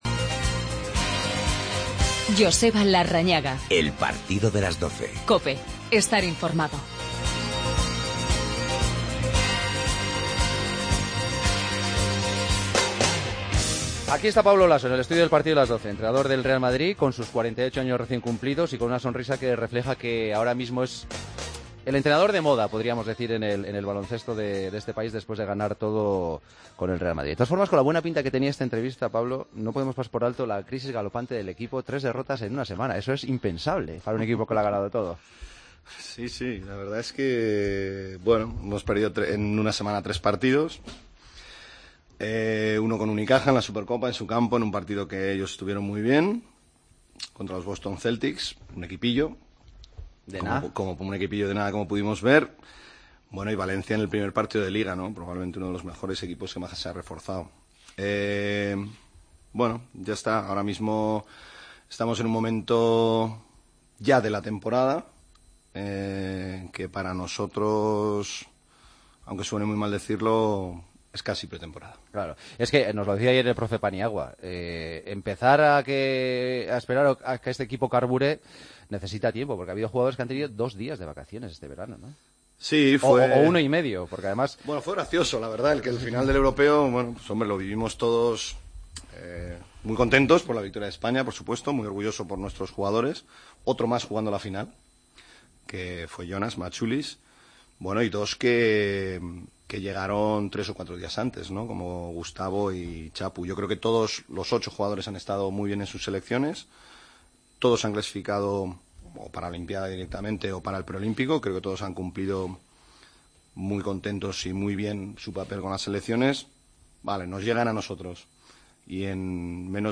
Entrevista a Pablo Laso en El Partido de las 12